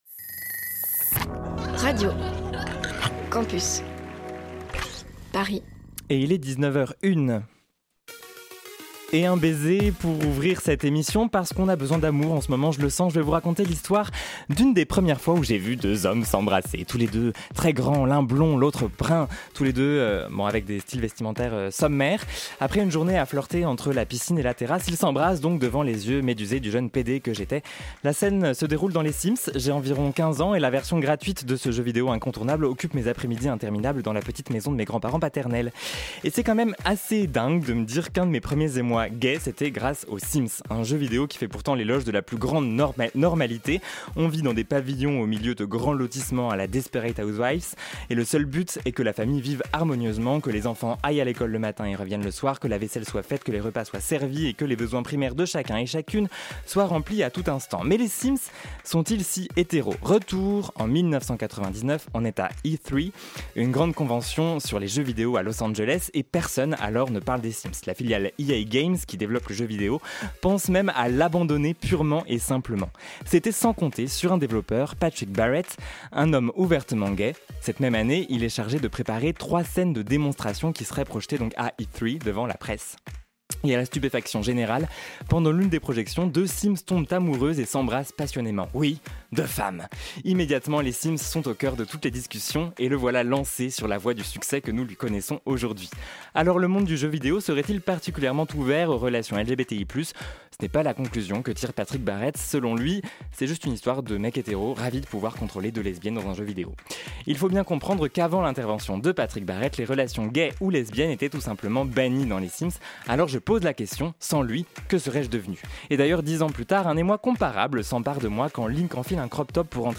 Alors que la Japan Expo vient de refermer ses portes, on joue les prolongations : parole aux queers geeks ce soir dans Le Lobby !